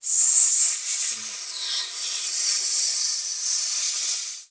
pgs/Assets/Audio/Animal_Impersonations/snake_2_hiss_slither_02.wav at master
snake_2_hiss_slither_02.wav